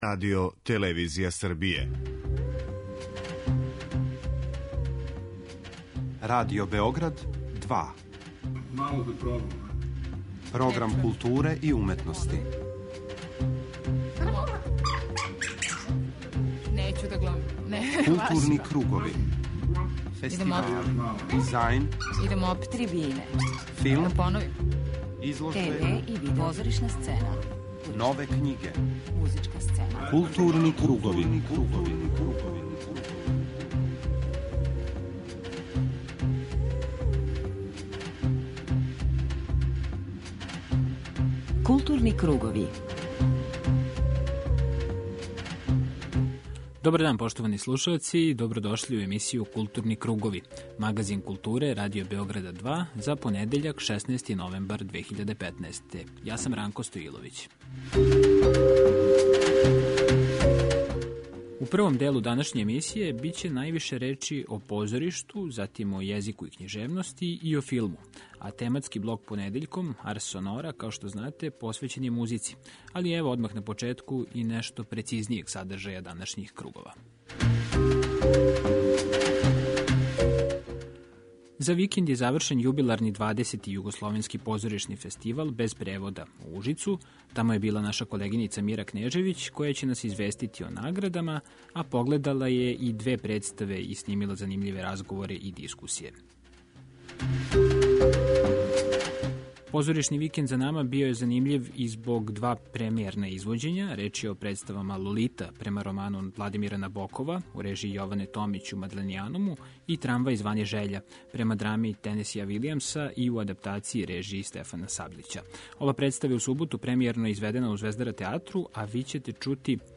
преузми : 40.64 MB Културни кругови Autor: Група аутора Централна културно-уметничка емисија Радио Београда 2.